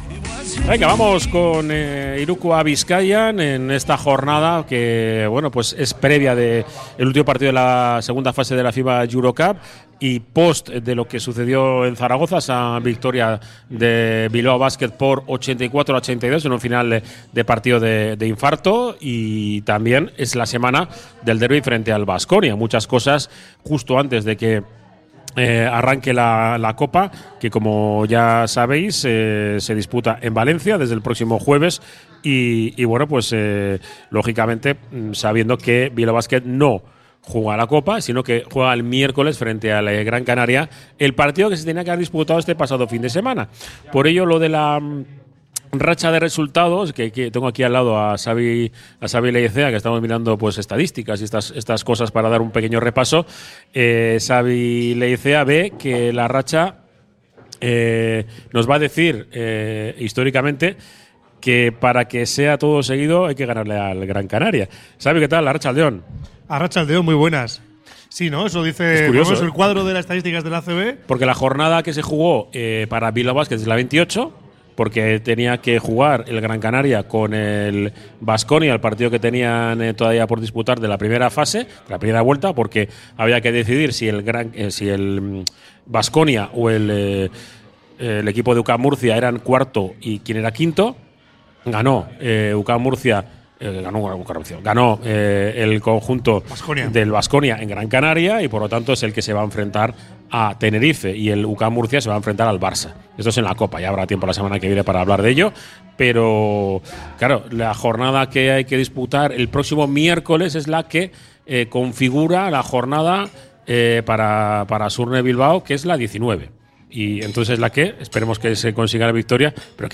Desde el Bar Izar la Quinta Estrella